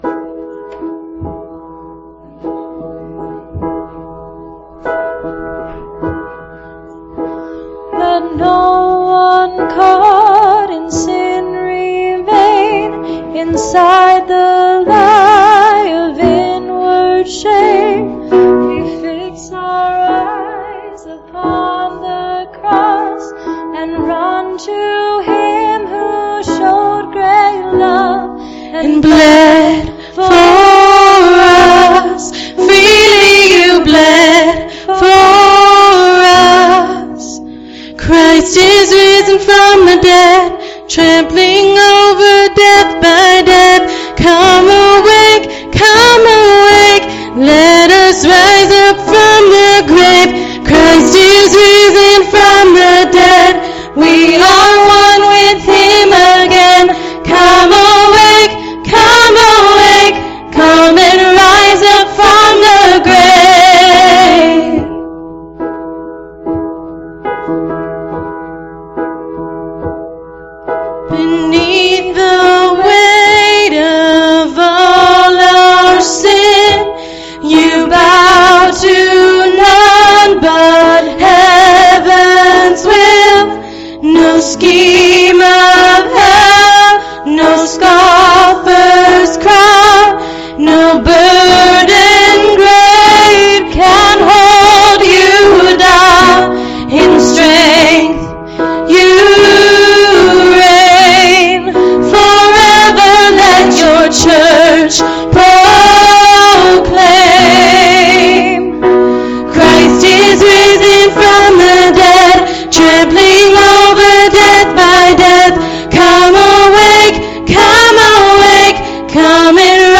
Please, click the arrow below to hear this week's service.